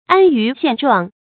注音：ㄢ ㄧㄩˊ ㄒㄧㄢˋ ㄓㄨㄤˋ
安于現狀的讀法